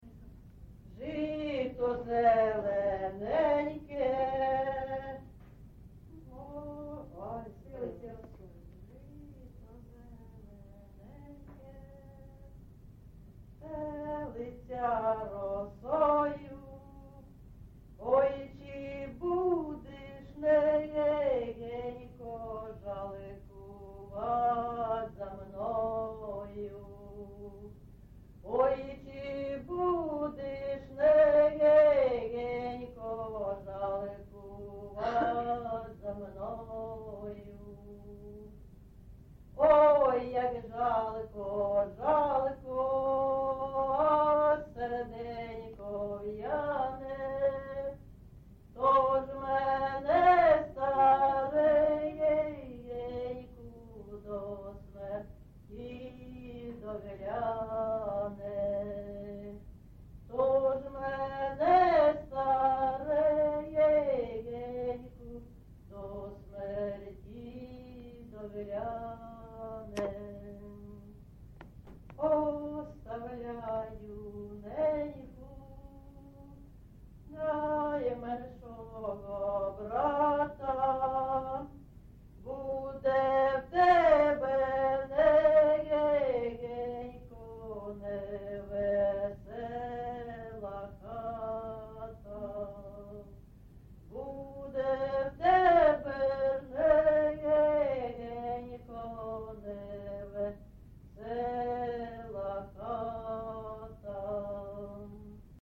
ЖанрПісні з особистого та родинного життя
Місце записус. Андріївка, Великоновосілківський район, Донецька обл., Україна, Слобожанщина